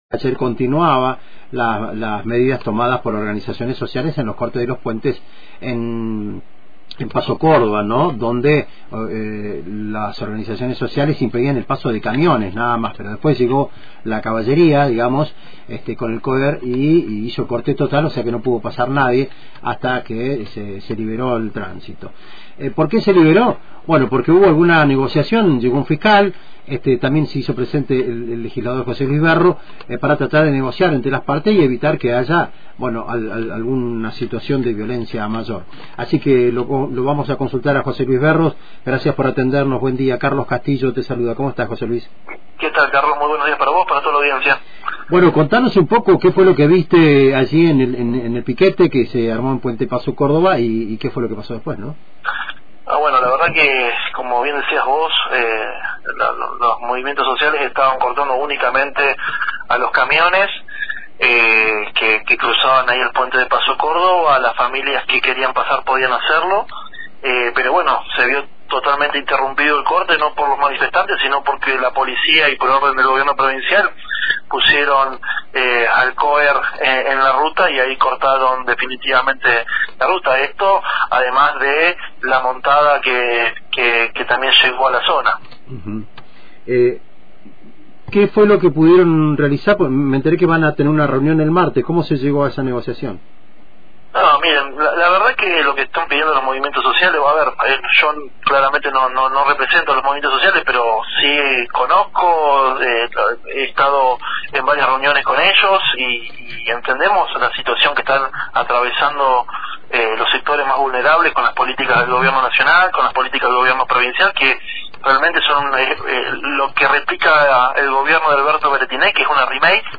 En diálogo con Antena Libre el Legislador José Luis Berros anticipó que seguramente la Justicia fallará en desmedro de los que menos tienen.